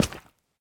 sounds / step / coral1.ogg
coral1.ogg